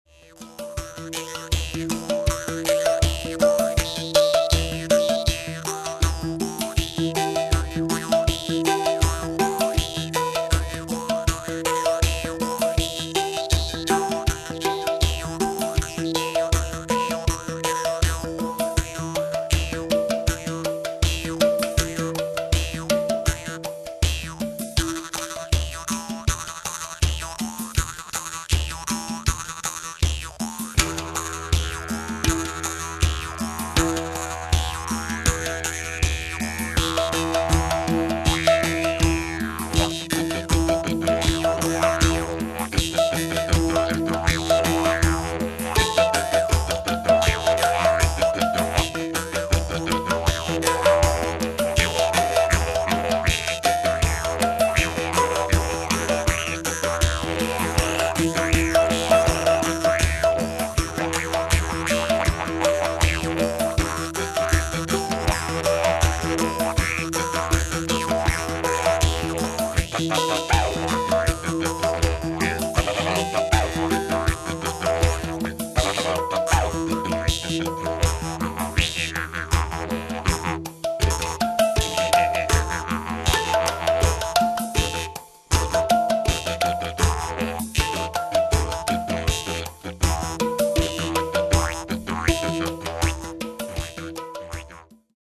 Hang_Didge (MP3, 1,1MB) Didgeridoo mit HANG, Maultrommel (Hmong Harp) und Drum-Box
Multitrack-Aufnahme
hang_didge.mp3